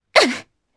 Epis-Vox_Attack1_jp.wav